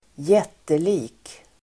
Uttal: [²j'et:eli:k]